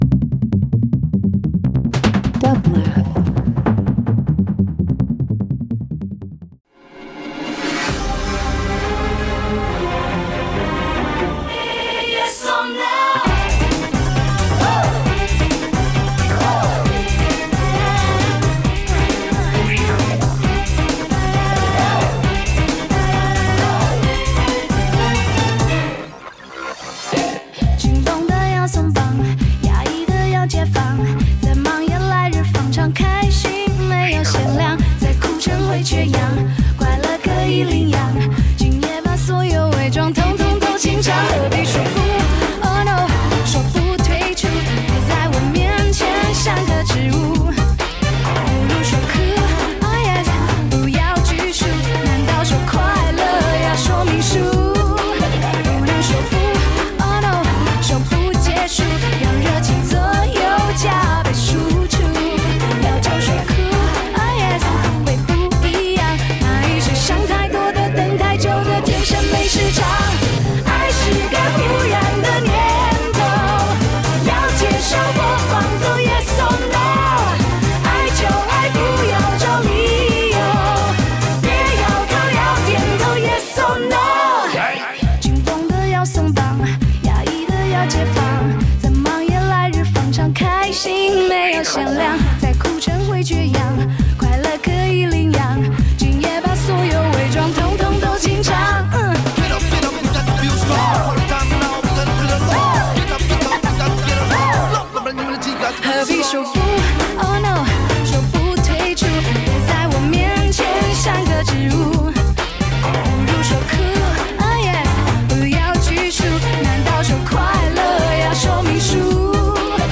Chinese Dance Pop